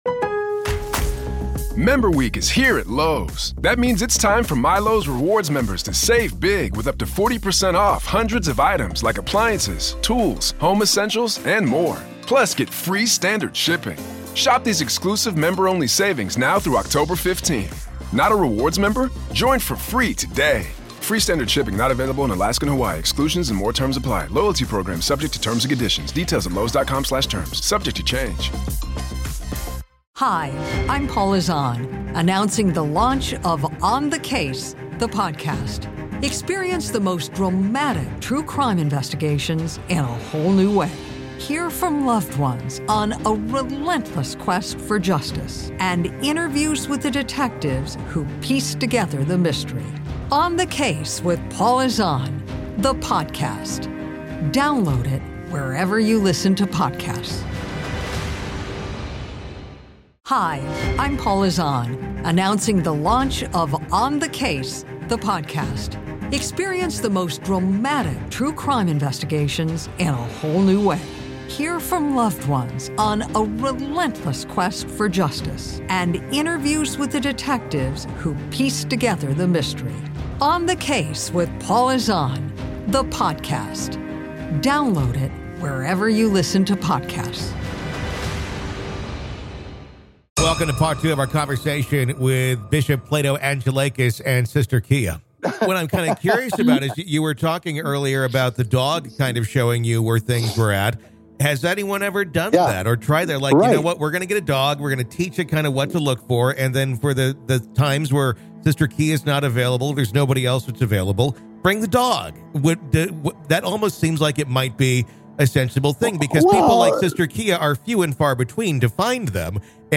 This is Part Two of our conversation.